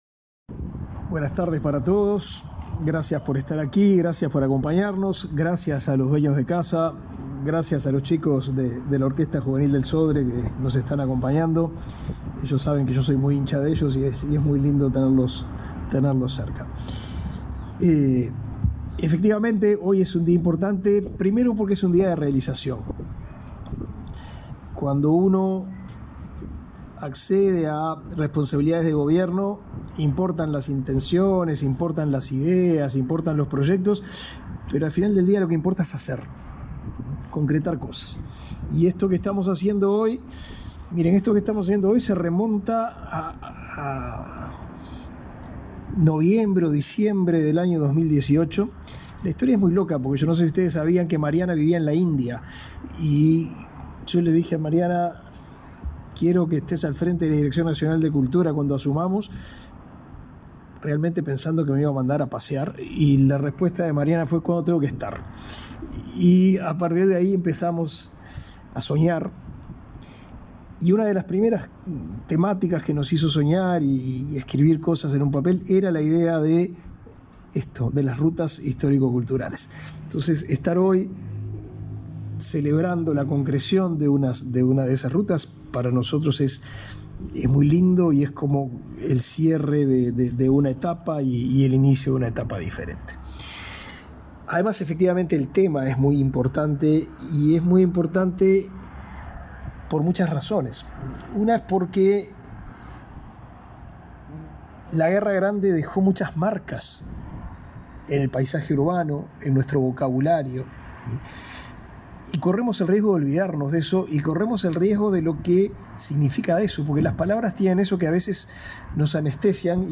Conferencia de prensa por el lanzamiento del programa Rutas Culturales